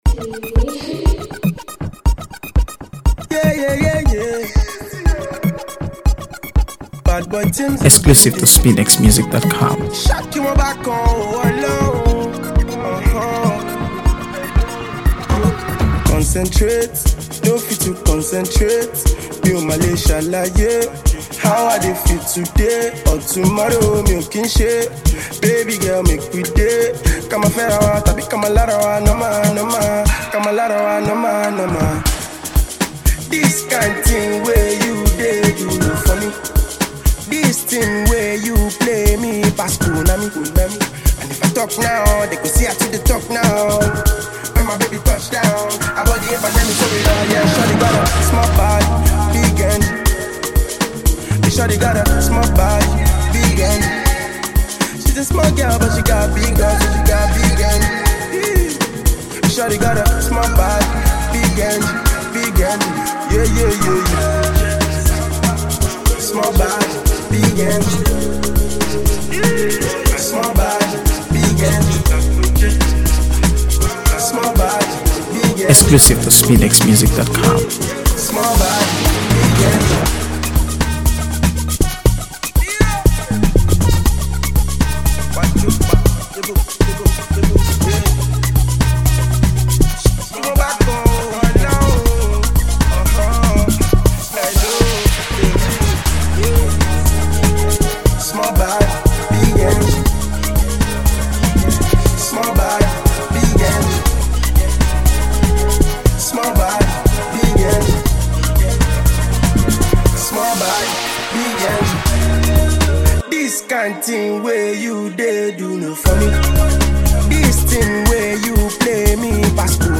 AfroBeats | AfroBeats songs
Driven by bouncing rhythms and catchy melodies